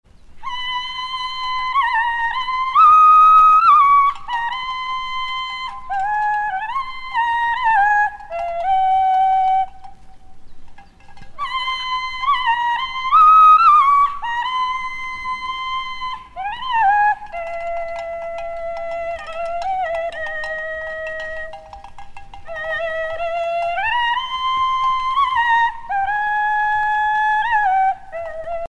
Herding call